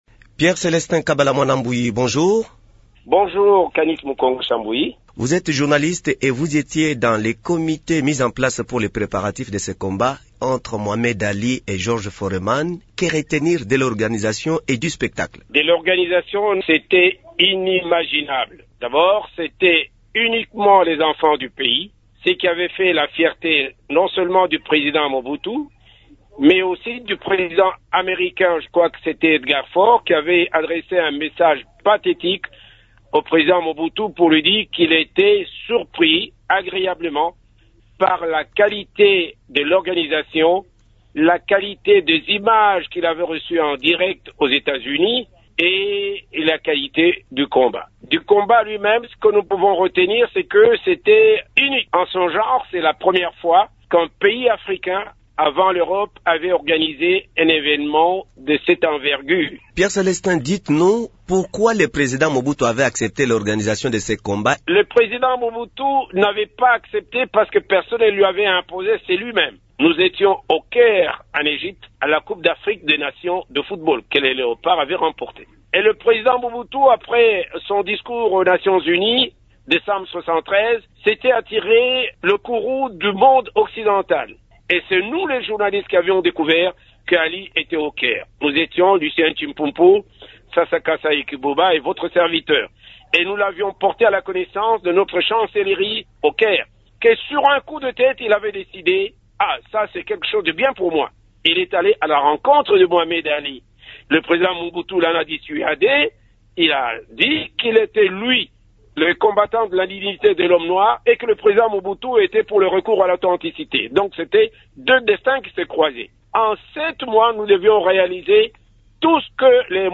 Il s’entretient